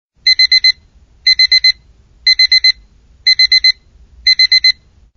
alarm_wav